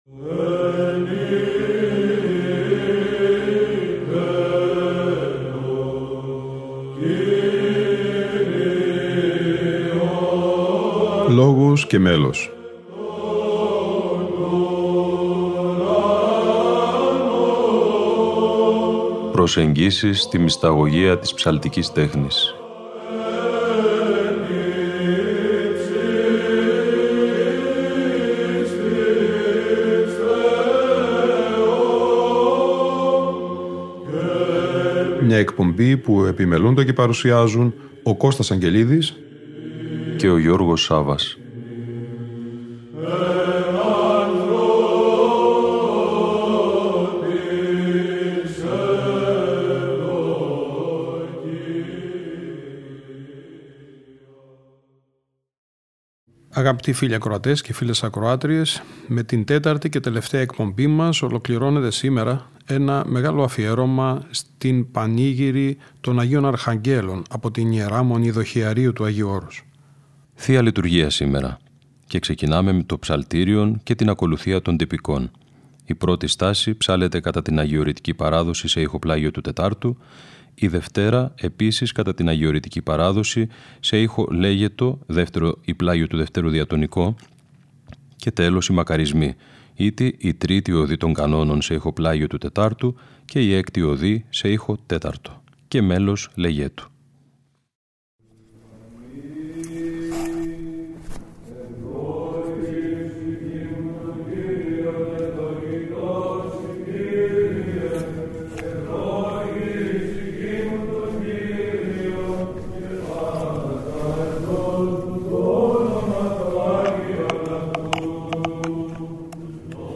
Αγρυπνία Αρχαγγέλων στην Ι.Μ. Δοχειαρίου (Δ΄)